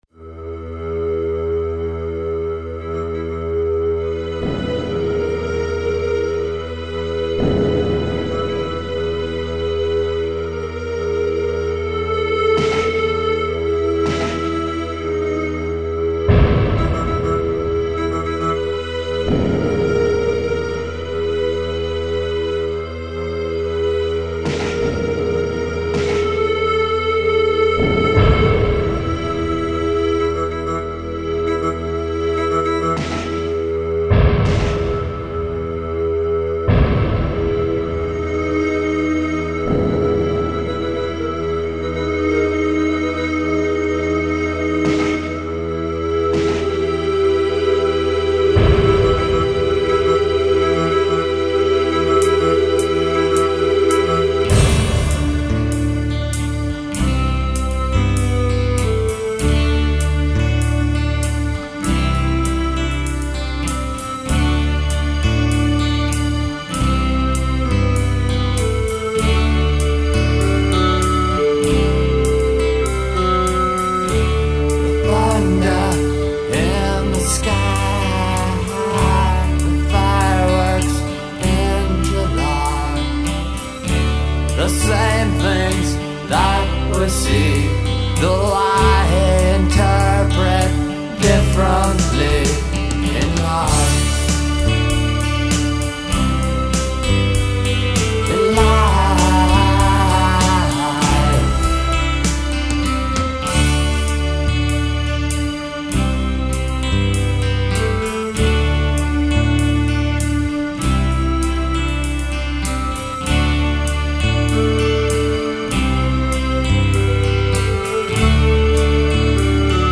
GENRE - Rock - Various Styles